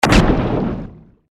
Royalty free sounds: Firearms
mf_SE-5436-harpoon_shot_1.mp3